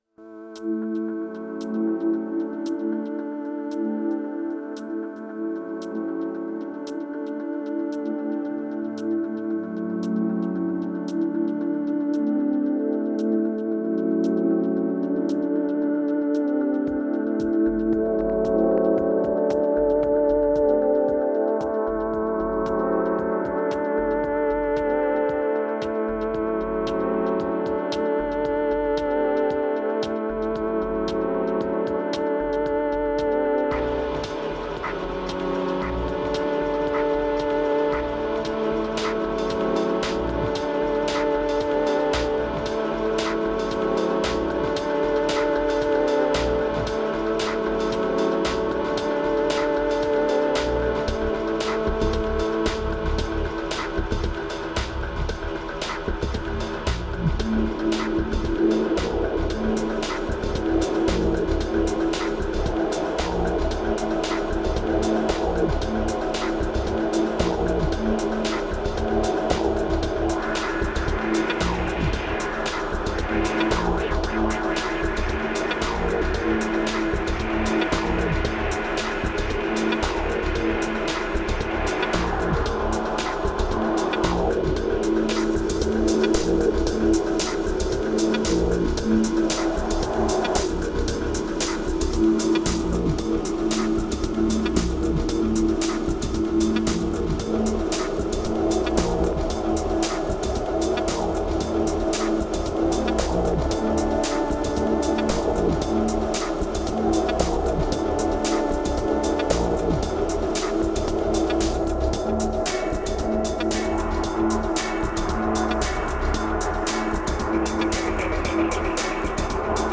2938📈 - 67%🤔 - 114BPM🔊 - 2017-02-08📅 - 495🌟